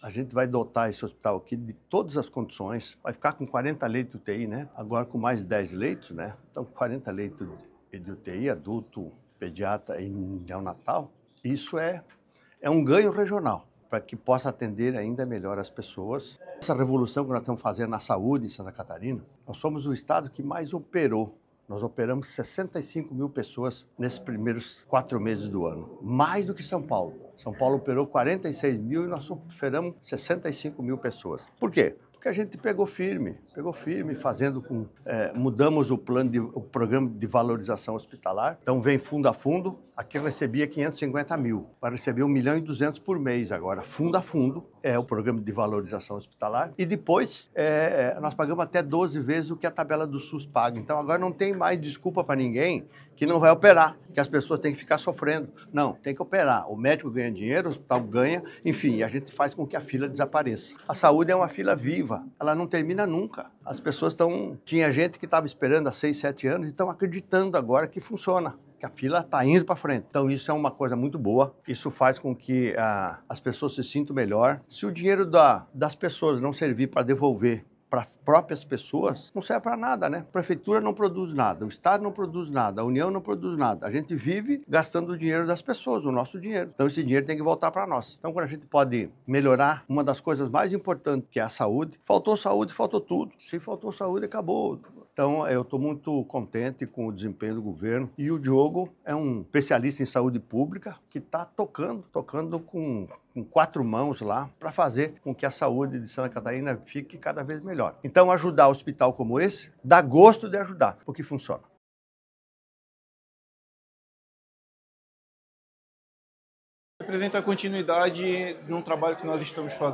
O governador Jorginho Mello e o secretário de Estado da Saúde, Diogo Demarchi, participaram nesta segunda-feira, 22, da inauguração de 10 leitos de Unidade de Terapia Intensiva (UTI) pediátrica no Hospital e Maternidade Oase, em Timbó.
O governador Jorginho Mello afirmou que essa entrega faz parte da revolução que está sendo feita na Saúde de Santa Catarina:
O secretário Diogo Demarchi ressalta a determinação do governador em tornar a saúde cada vez mais acessível para a população: